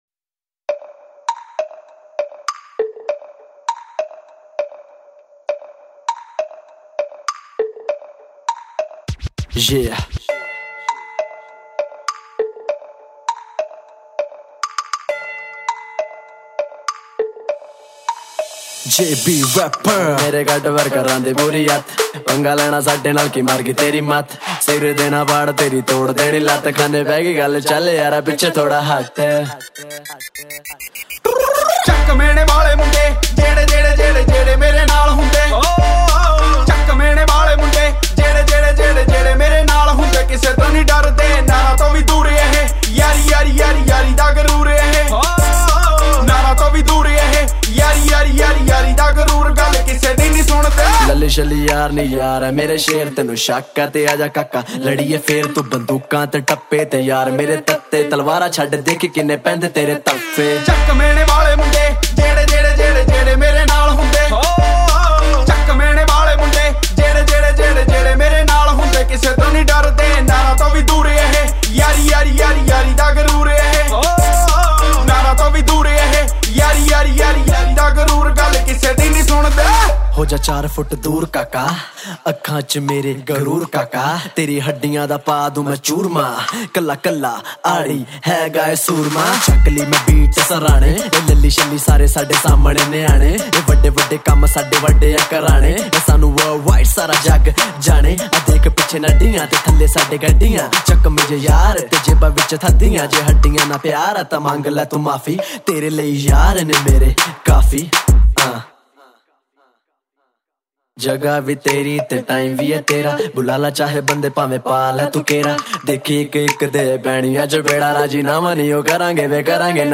Punjabi Bhangra MP3 Songs